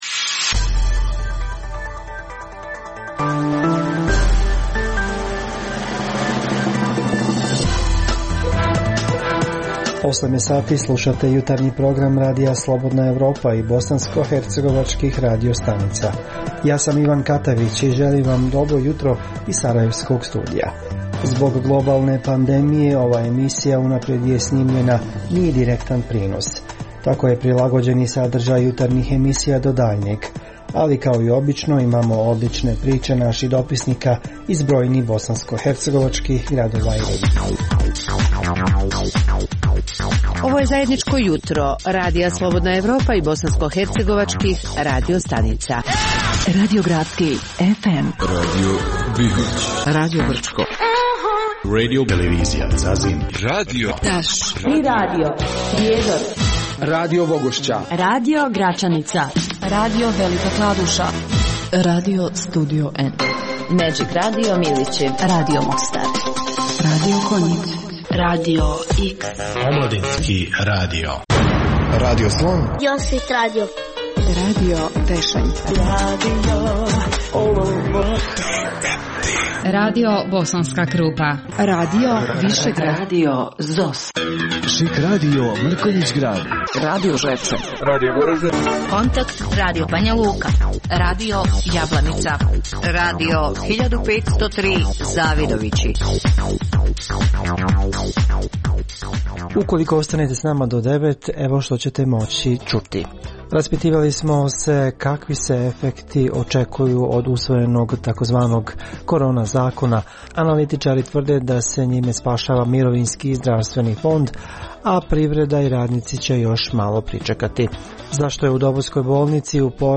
Zbog pooštrenih mjera kretanja u cilju sprječavanja zaraze korona virusom, ovaj program je unaprijed snimljen. Poslušajte neke od priča iz raznih krajeva Bosne i Hercegovine.